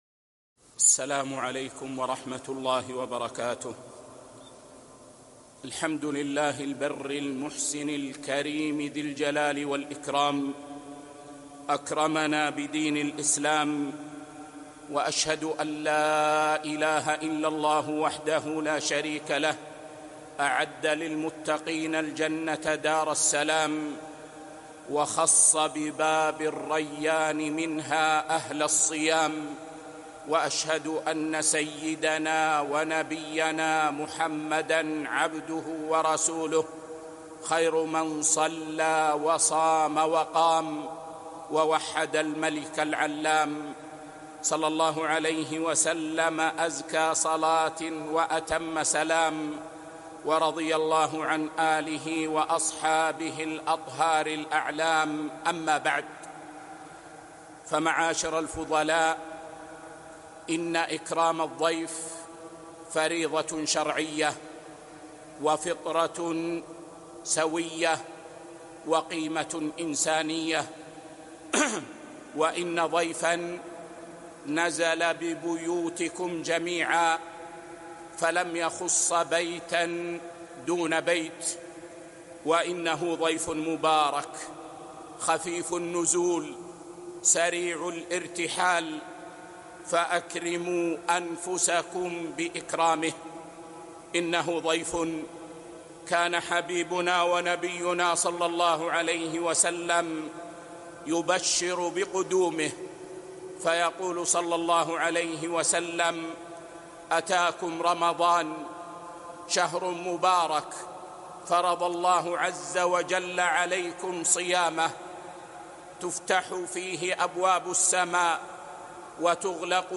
كلمة: أتاكم رمضان | 1 رمضان 1445 بمسجد قباء